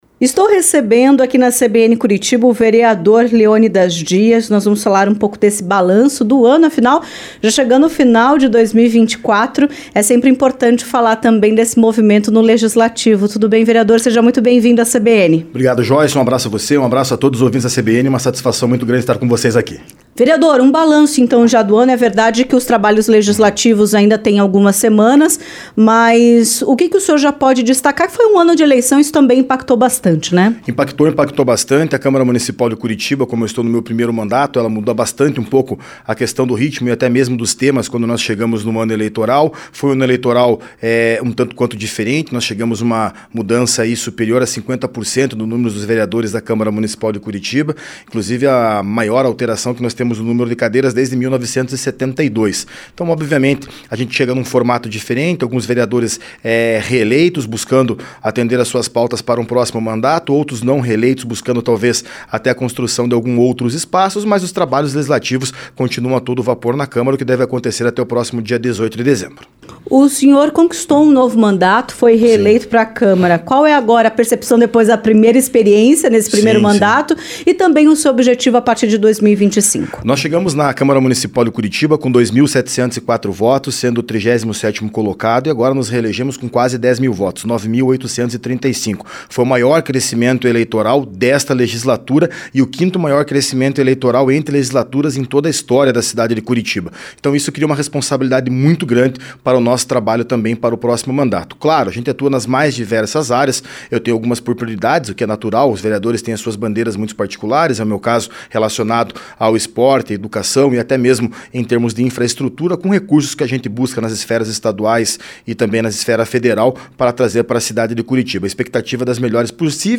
ENTREVISTA-VEREADOR-LEONIDAS-DIAS.mp3